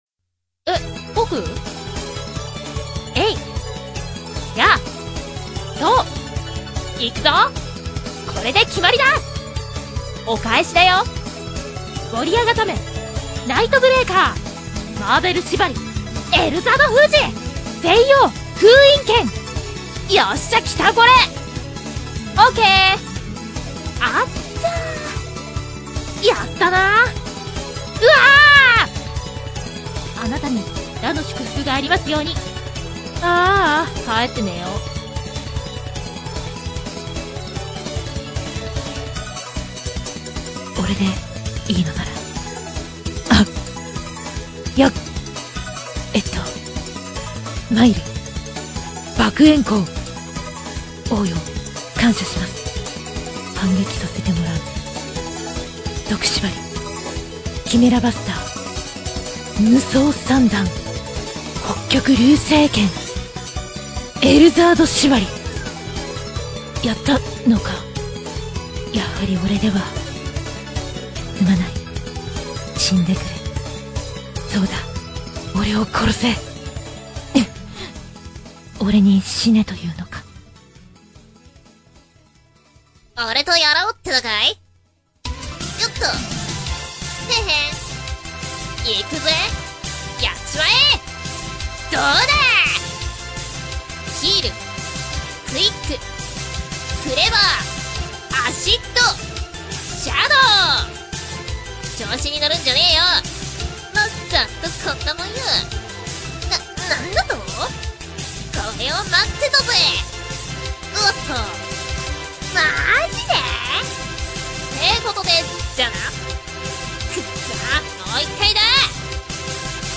最近のぷよぷよは連鎖ボイスがすごいんですよ！ということを表現したかったのだが、ぷよぷよをプレイしたことがないと意味不明。
puyopuyovoiceshort.mp3